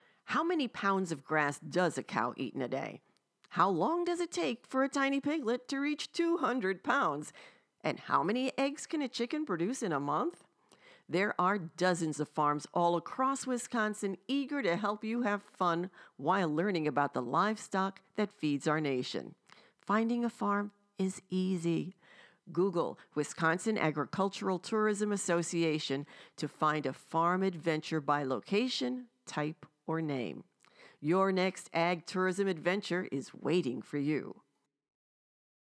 Wisconsin Agricultural Tourism – Public Service Announcements (PSAs)